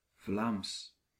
Ääntäminen
Synonyymit néerlandais hollandais langue de Vondel pain suisse Ääntäminen France: IPA: [fla.mɑ̃] Haettu sana löytyi näillä lähdekielillä: ranska Käännös Konteksti Ääninäyte Substantiivit 1.